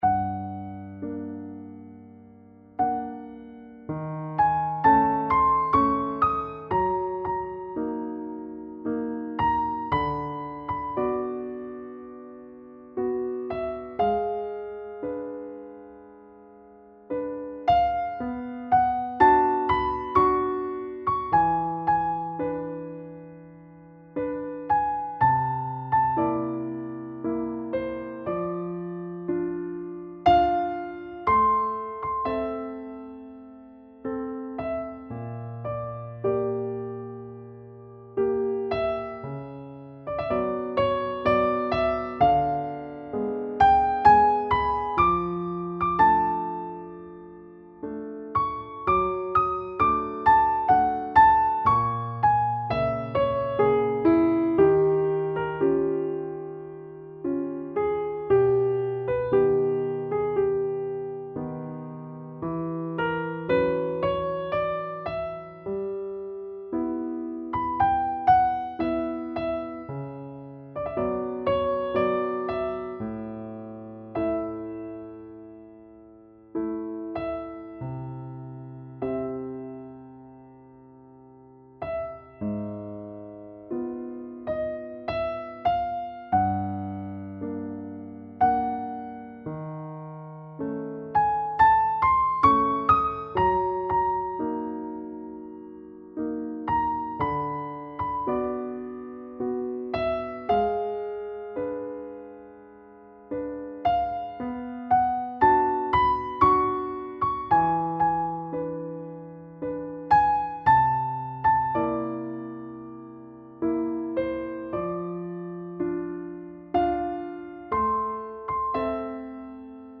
nocturne-3-piano-solo.mp3